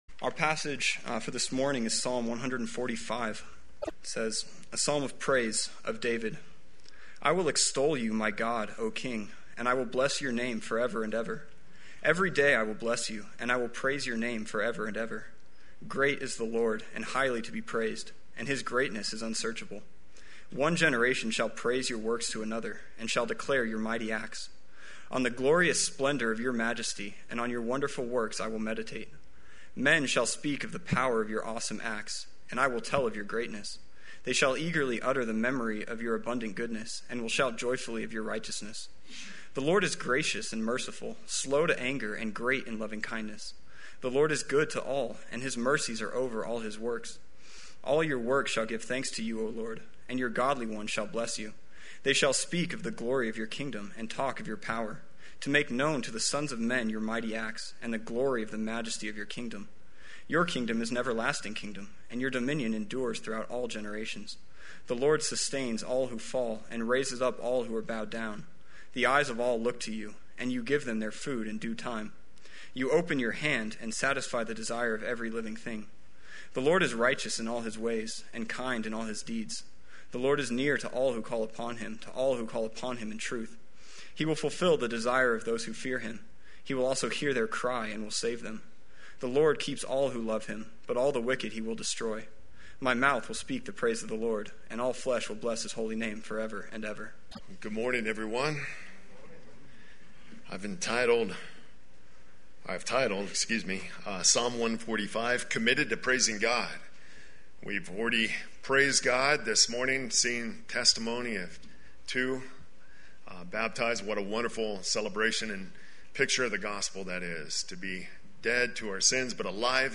Play Sermon Get HCF Teaching Automatically.
Committed to Praising God Sunday Worship